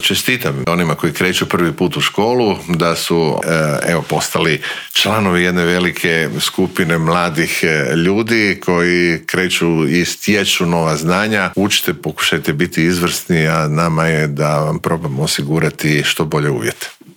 ZAGREB - U ponedjeljak će zvono označiti početak nove školske godine, a prije nego što se školarci vrate pred ploču, pred mikrofon Media servisa u Intervjuu tjedna stao je ministar znanosti i obrazovanja Radovan Fuchs.